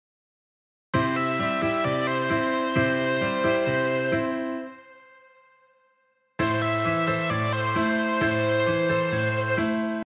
同じメロディーでも、コードがメジャーかマイナーかで聞こえ方が変わります。
↓の音源は、前半がCメジャー、後半がAmです。